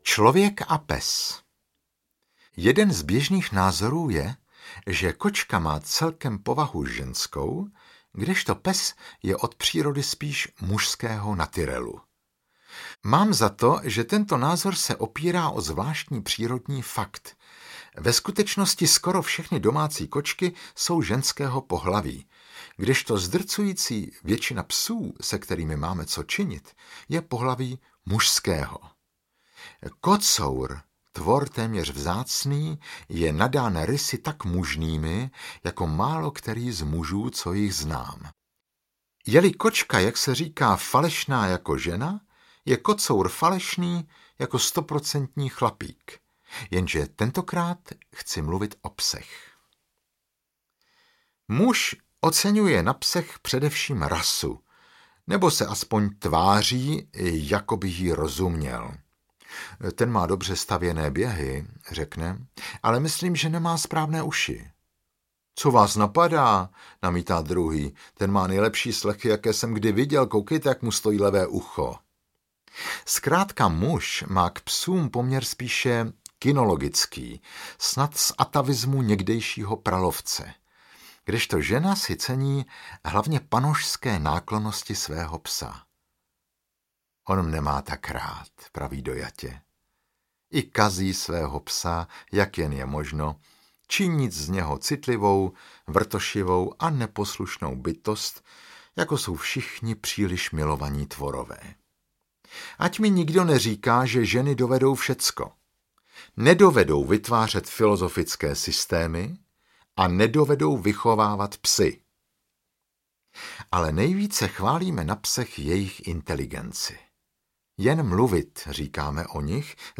Audio knihaPudlenka aneb Měl jsem psa a kočku
Ukázka z knihy
• InterpretMarek Eben